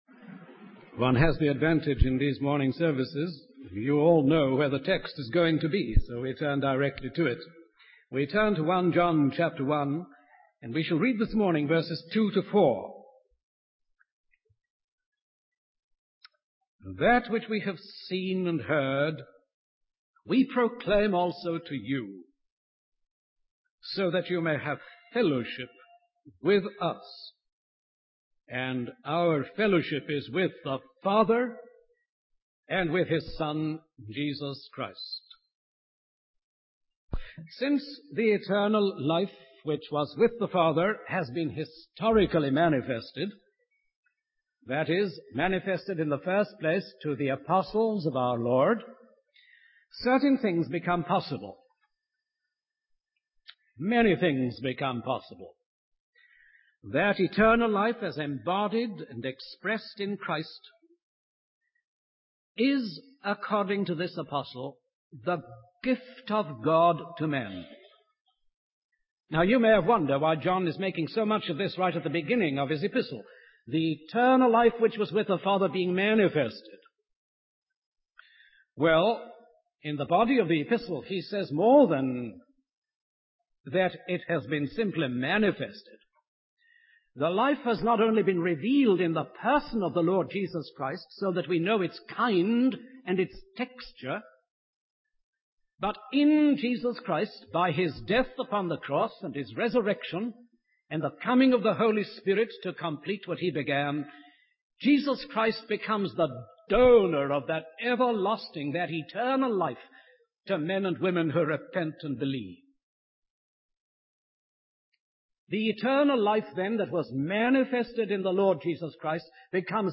In this sermon, the speaker emphasizes the importance of fellowship and sharing among believers.